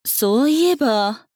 大人女性│女魔導師│リアクションボイス│商用利用可 フリーボイス素材 - freevoice4creators